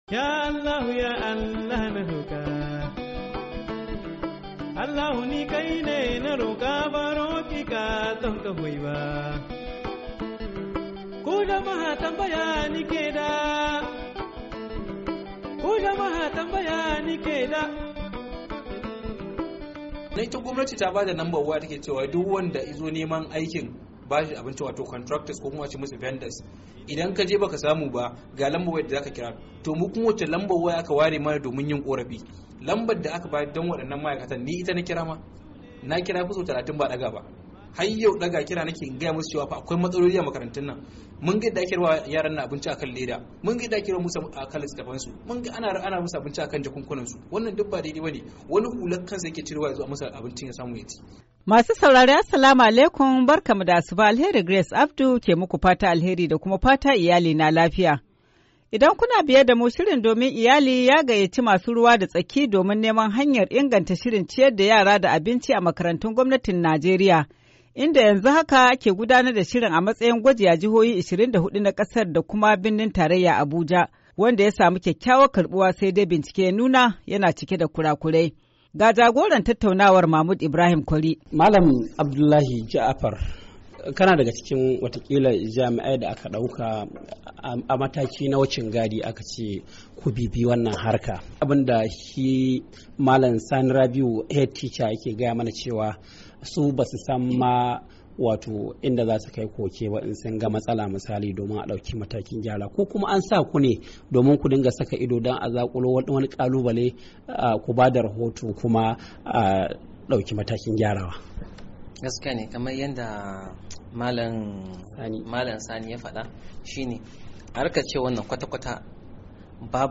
Tattaunawa kan ciyar da yara da abinci a makarantun Najeriya, Kashi na biyu-10:14"